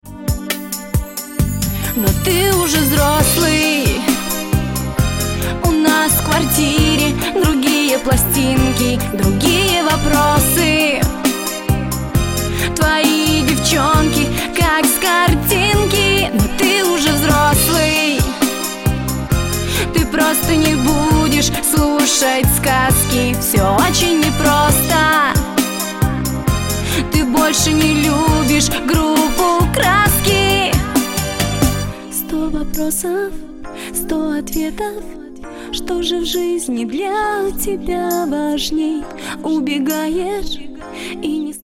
• Качество: 128, Stereo
грустные
ностальгия